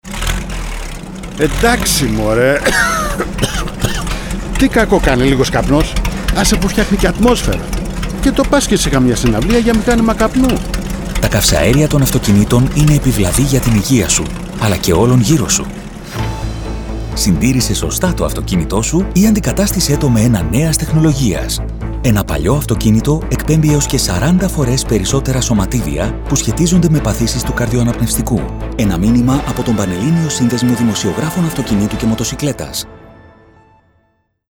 Ραδιοφωνικά μηνύματα σχετικά με τη δημόσια υγεία, την οδική ασφάλεια και την εξυπηρέτηση της κυκλοφορίας στα αστικά δίκτυα, μεταδίδονται από αρχές Ιουλίου (προς το παρόν σε περιορισμένο δίκτυο ραδιοφωνικών σταθμών του Λεκανοπεδίου).